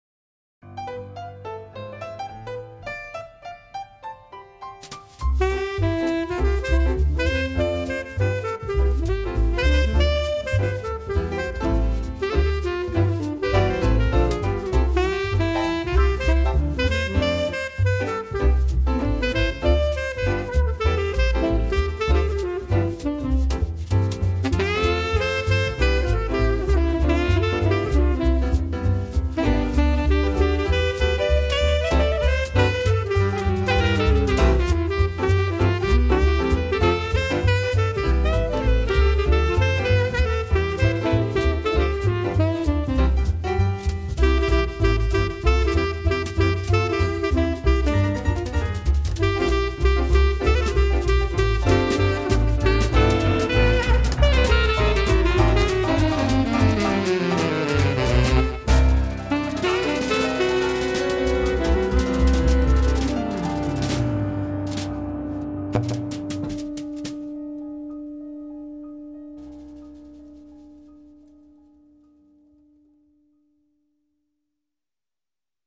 rendering familiar classics with a new beat